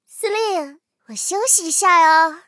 M10狼獾小破修理语音.OGG